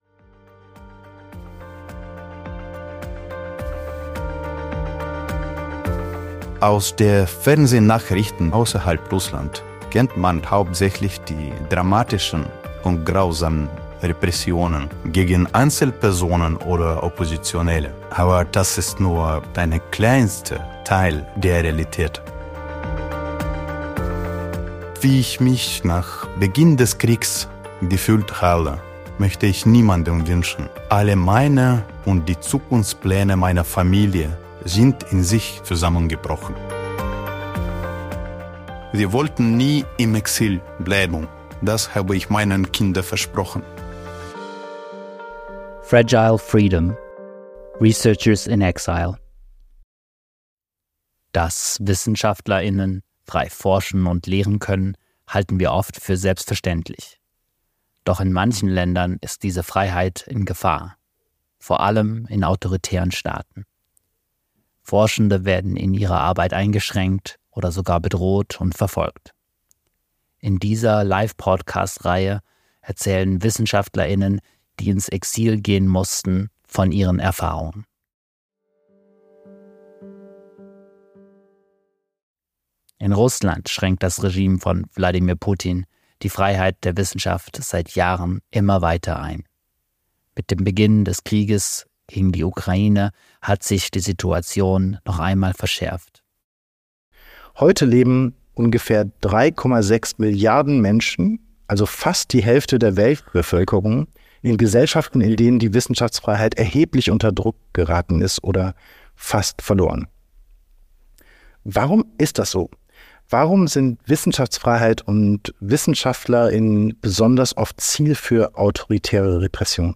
*Zum Schutz seiner Identität wurde der Name des Wissenschaftlers geändert und seine Stimme mithilfe künstlicher Intelligenz verfremdet. Seit Jahren wird die Wissenschaftsfreiheit in Russland durch Wladimir Putins Regime eingeschränkt. Mit Beginn des Krieges in der Ukraine verschärfte sich die Lage gerade für Forschende, die nicht mit dem Krieg einverstanden sind.